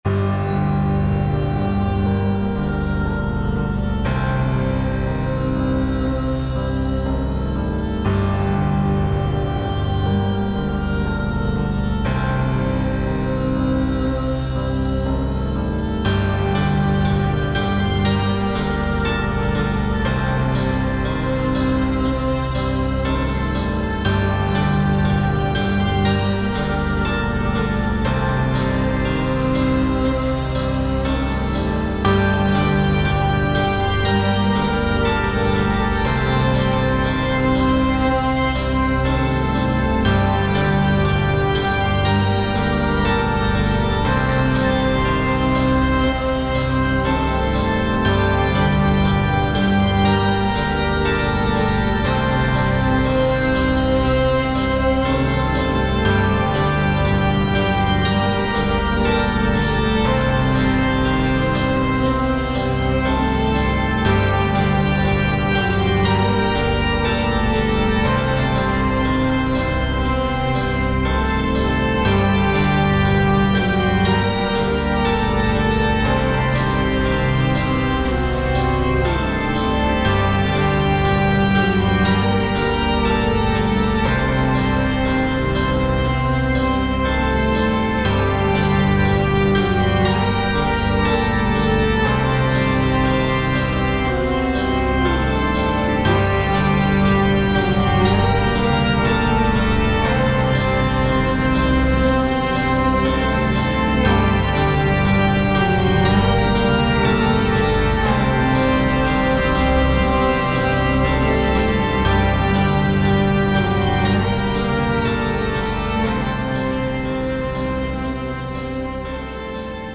タグ: ドキドキ/緊張感 ファンタジー 不気味/奇妙 暗い 虚無/退廃 コメント: ダークでゴシックな雰囲気のBGM。